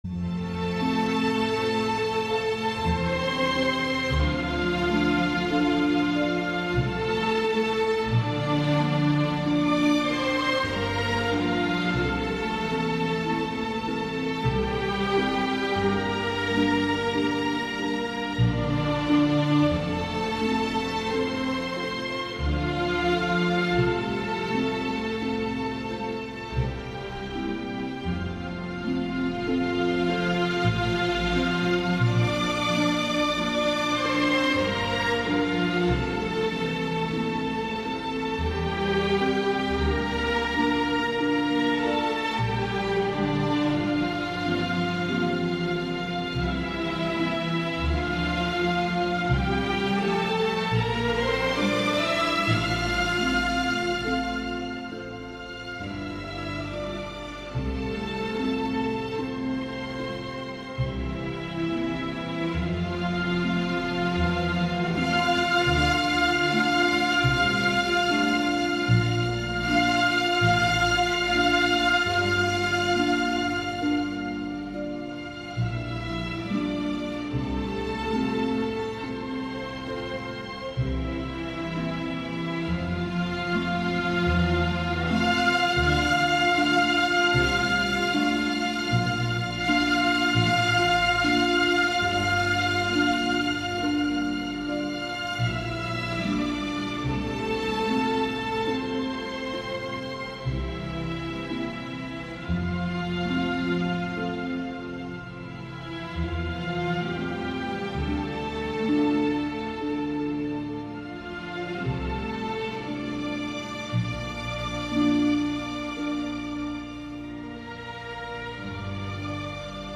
Масканьи – интермеццо из оперы «Сельская честь» (Cavalleria rusticana)